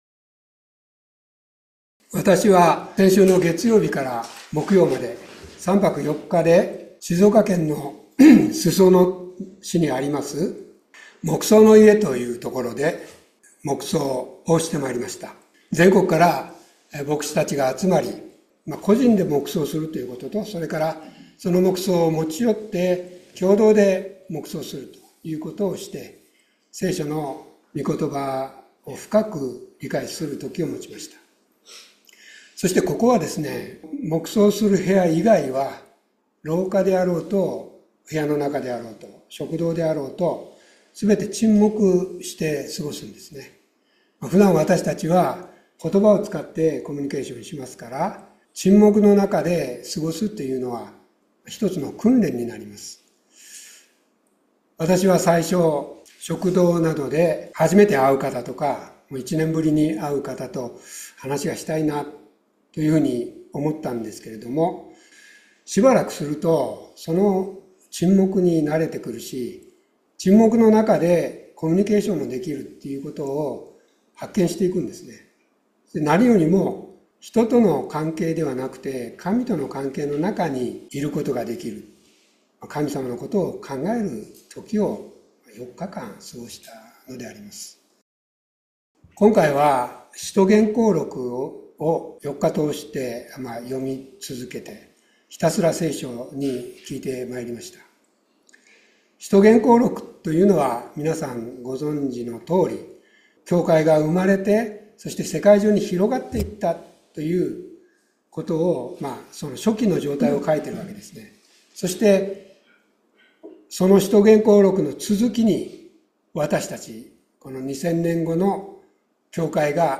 2月2日礼拝説教「傲慢を咎める主」